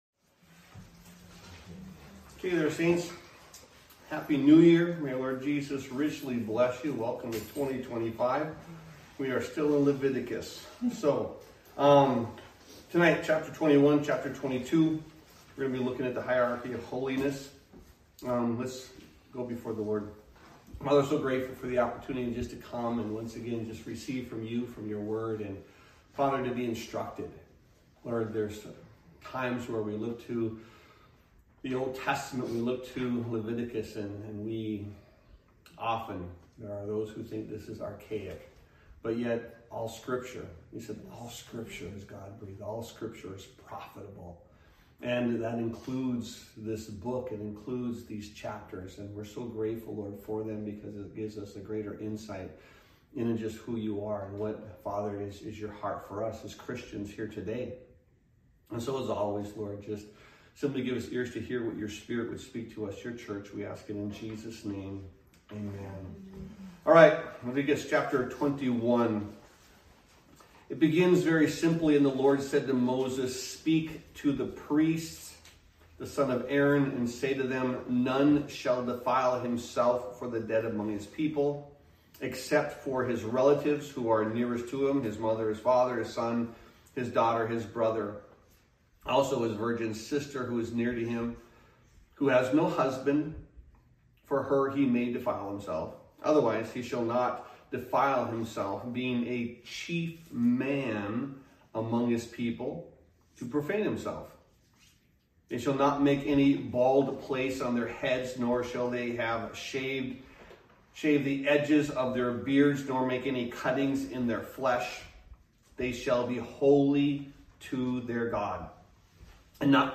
Sermons | Calvary Chapel Milwaukee